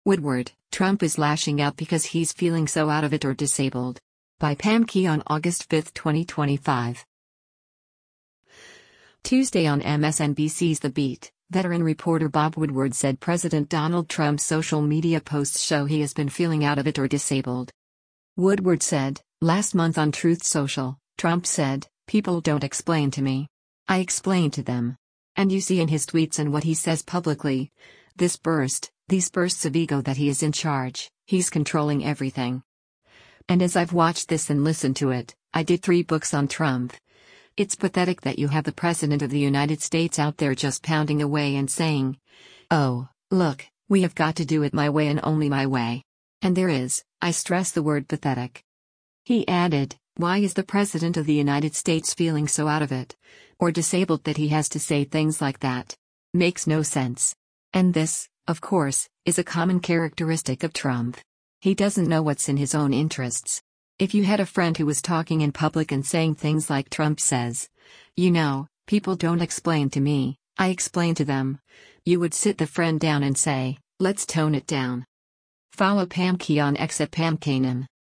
Tuesday on MSNBC’s”The Beat,” veteran reporter Bob Woodward said President Donald Trump’s social media posts show he has been feeling “out of it or disabled.”